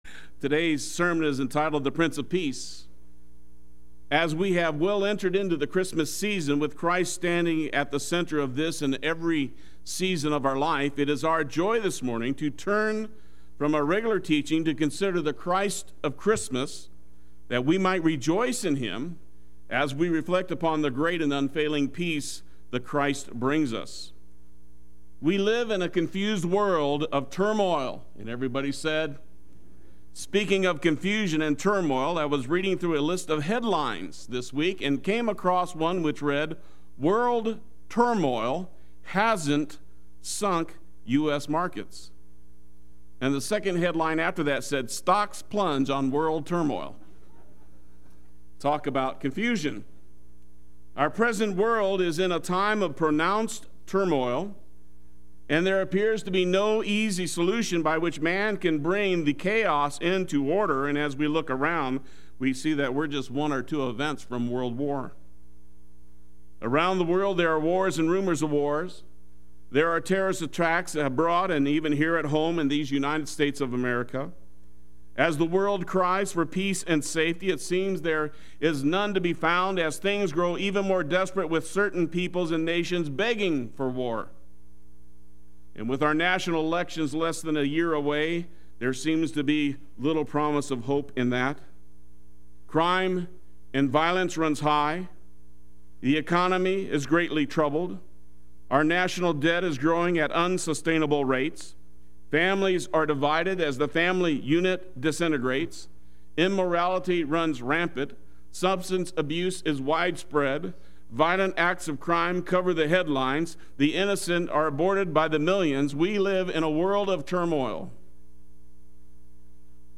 Play Sermon Get HCF Teaching Automatically.
The Prince of Peace Sunday Worship